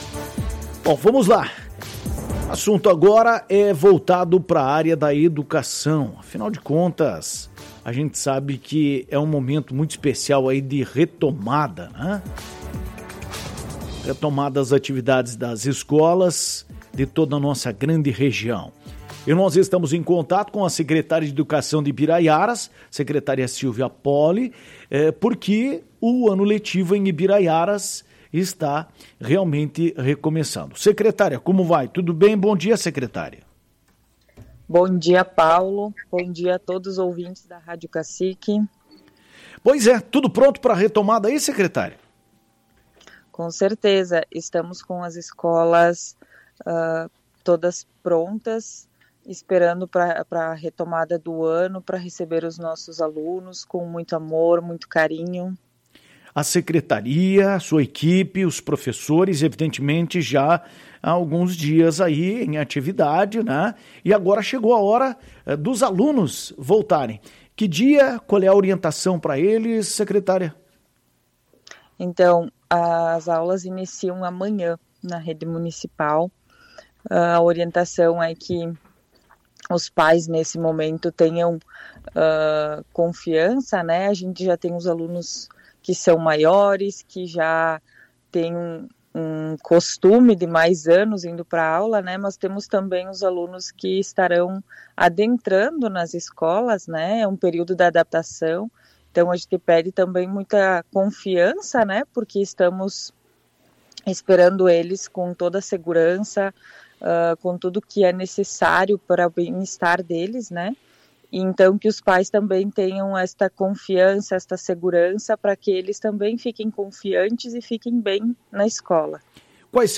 Em entrevista à Tua Rádio Cacique, a secretária da Educação, Silvia Polli, falou sobre a organização.